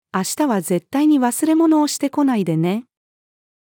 明日は絶対に忘れ物をしてこないでね！-female.mp3